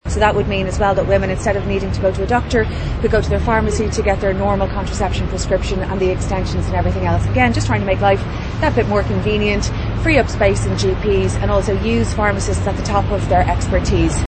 Minister Jennifer Carroll MacNeill says she wants to make it easier for women to access contraception: